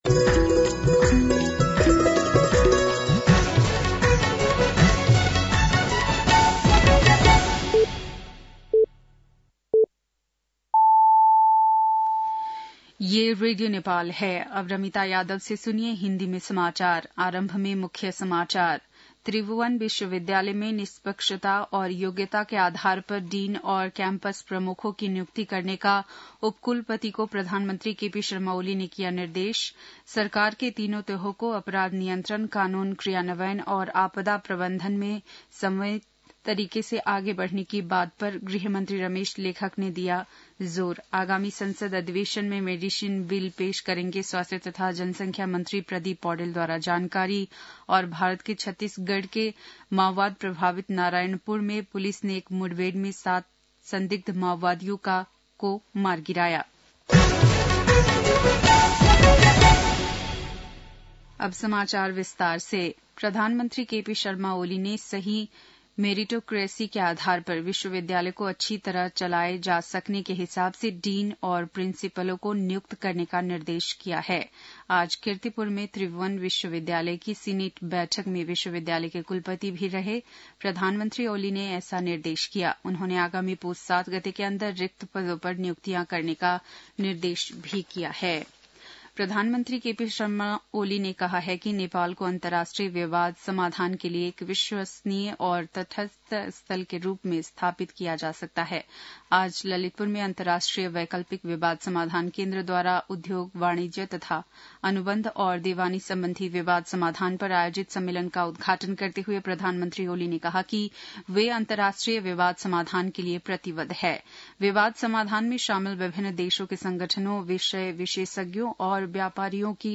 बेलुकी १० बजेको हिन्दी समाचार : २८ मंसिर , २०८१
10-PM-Hindi-NEWS-8-27.mp3